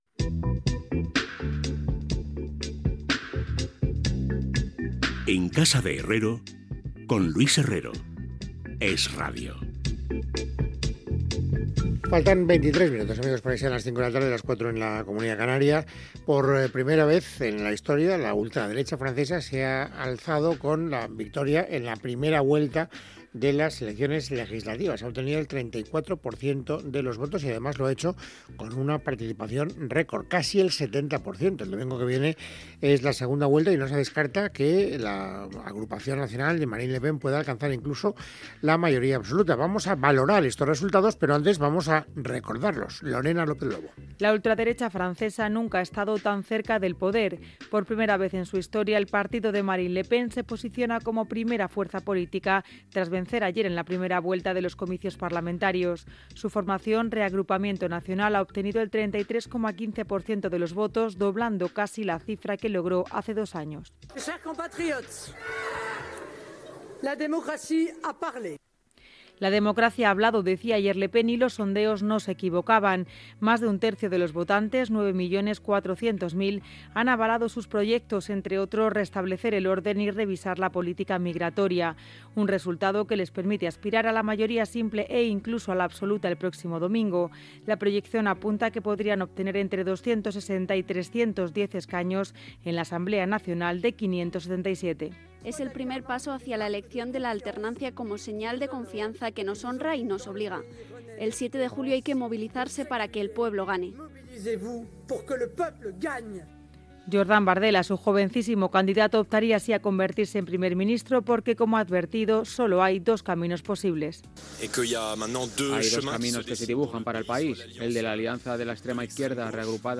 Info-entreteniment
DAB